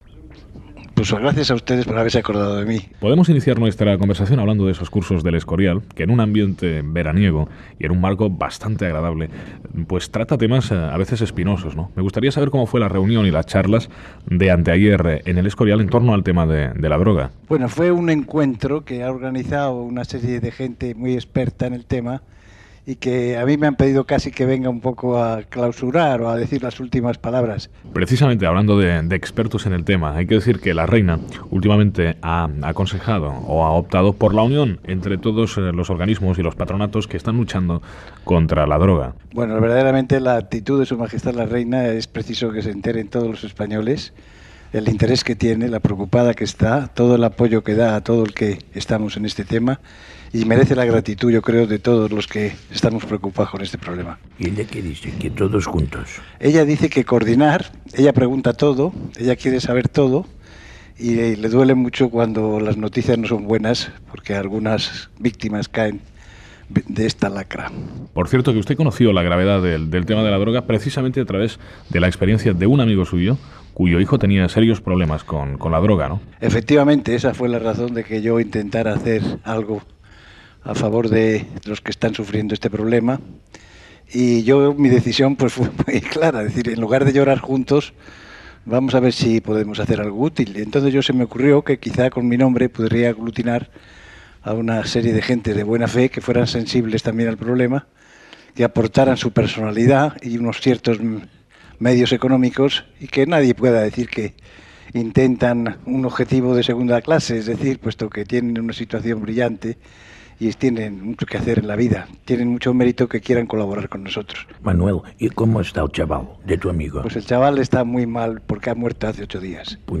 Fragment d'una entrevista al tinent general Manuel Gutiérrez Mellado sobre els Cursos de El Escorial sobre la droga
Fragment d'una entrevista extreta de RNEaudio.